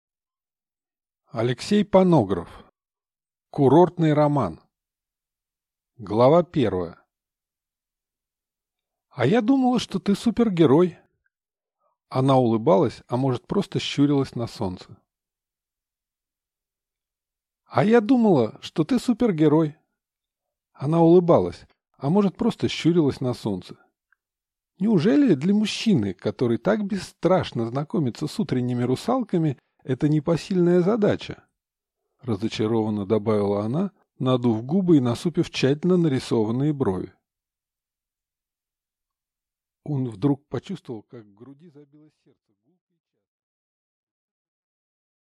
Аудиокнига Курортный роман | Библиотека аудиокниг